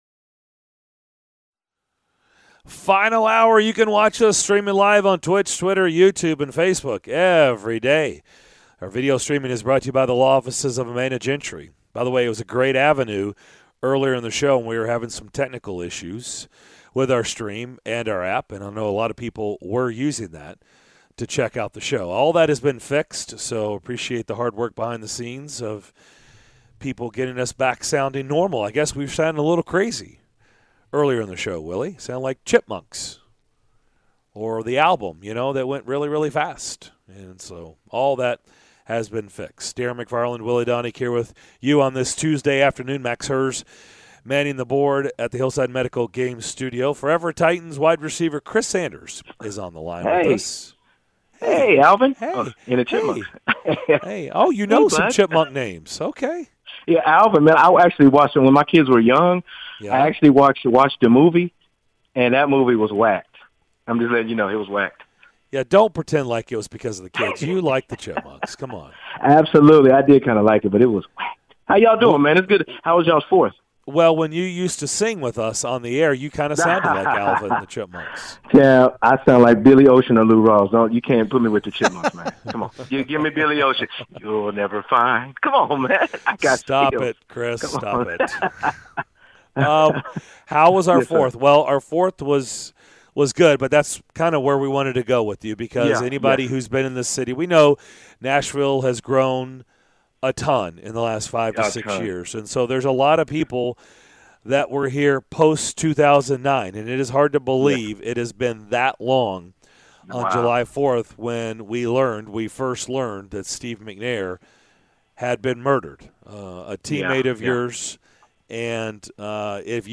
Chris Sanders Interview